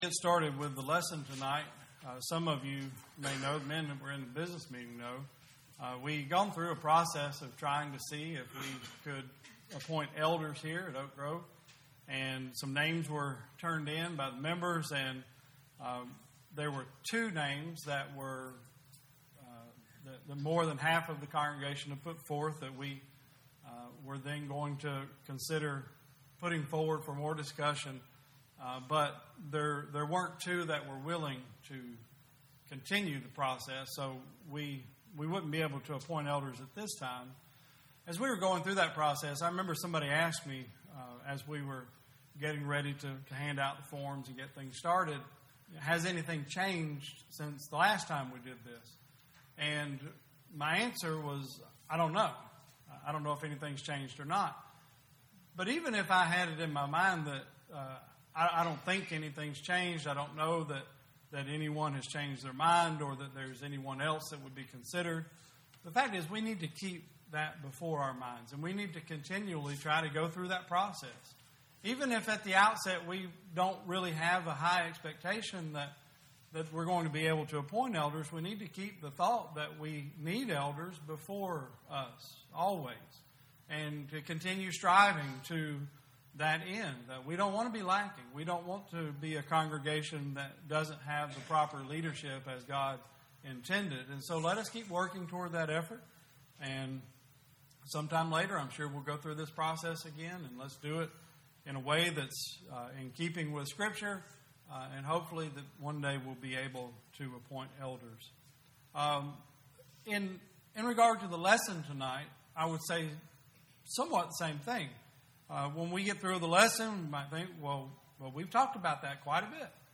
2019 Service Type: Sunday Service Topics